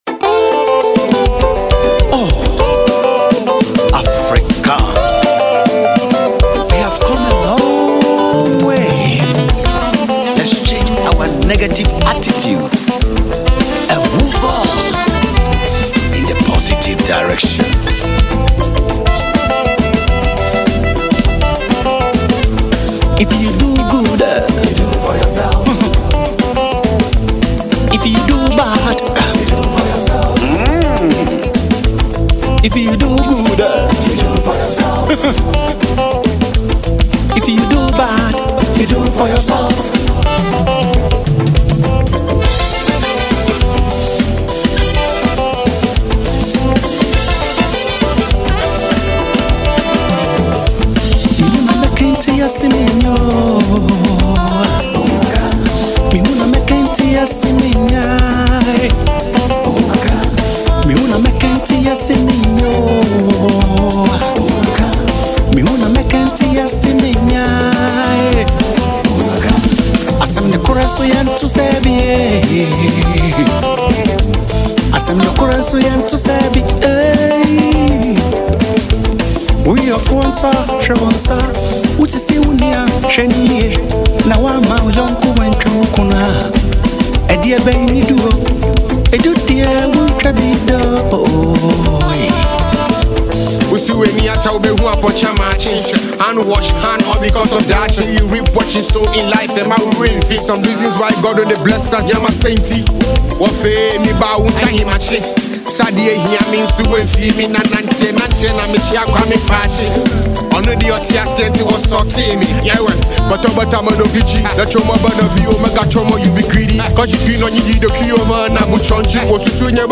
Veteran Ghanaian Highlife Singer